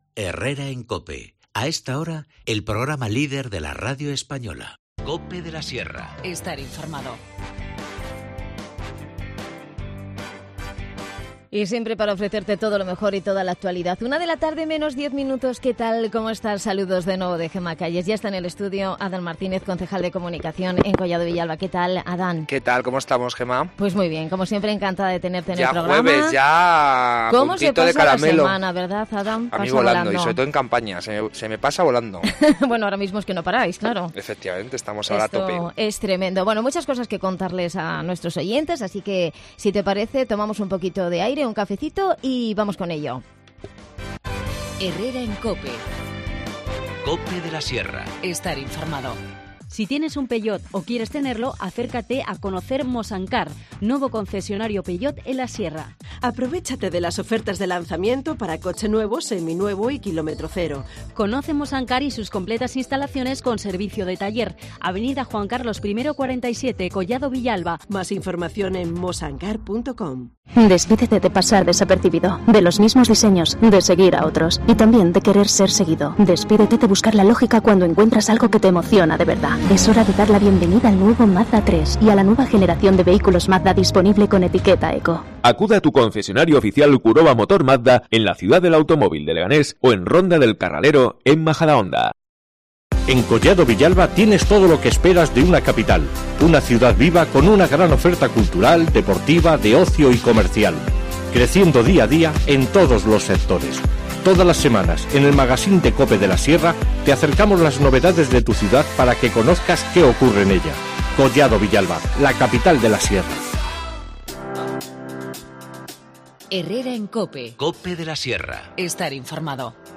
Adan Martínez, concejal de comunicación en Collado Villalba, nos habla de las actividades que han organizado para los próximos días. Vete preparando para el III Festival de la Cerveza Artesana y las fiestas de San José Obrero en barrio de El Gorronal.